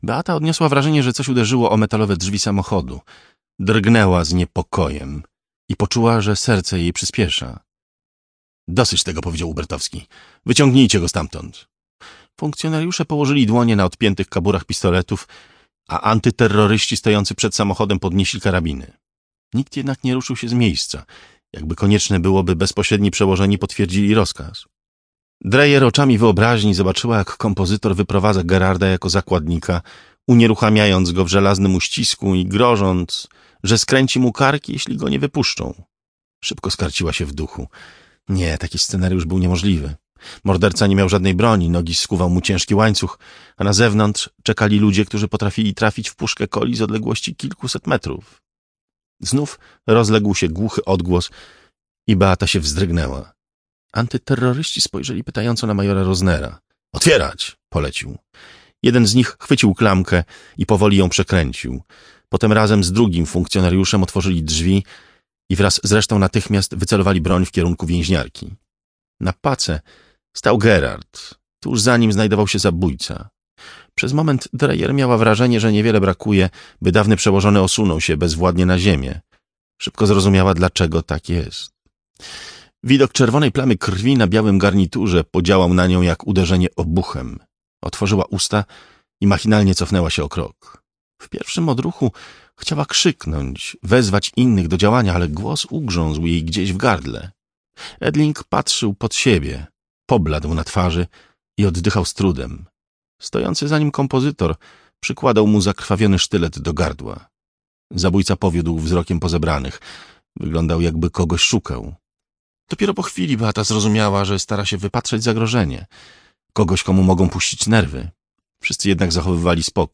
Trzymającej w napięciu powieści kryminalnej „Behawiorysta” Remigiusza Mroza w interpretacji Jacka Rozenka słuchaj w Radiu Kielce od poniedziałku do piątku o godz. 10.45 i 21.45.